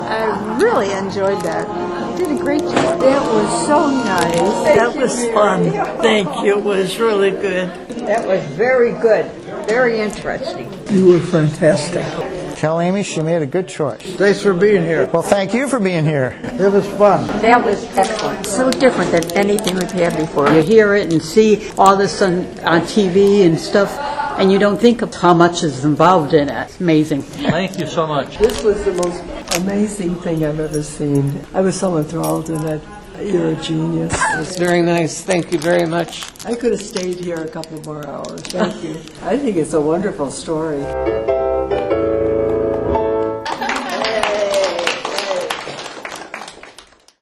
Seniors enthralled by the story: